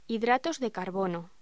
Locución: Hidratos de carbono